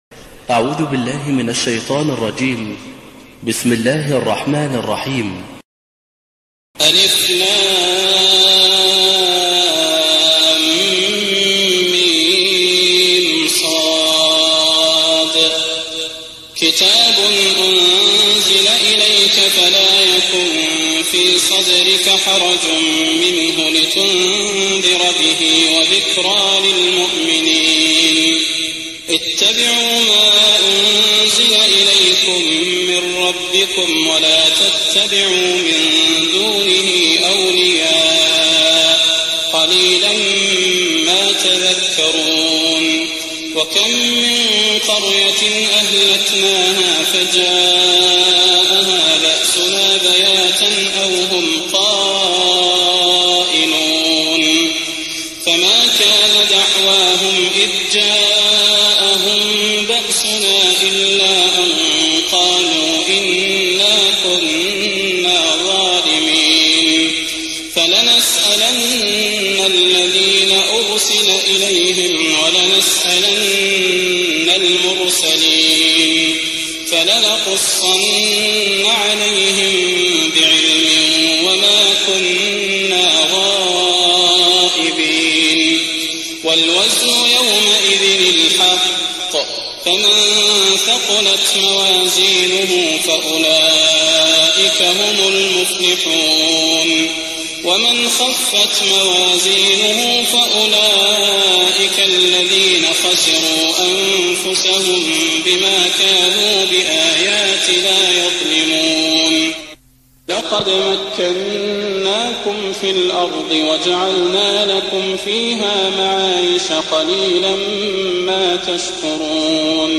تهجد ليلة 27 رمضان 1419هـ من سورة الأعراف (1-141) Tahajjud 27th night Ramadan 1419H from Surah Al-A’raf > تراويح الحرم النبوي عام 1419 🕌 > التراويح - تلاوات الحرمين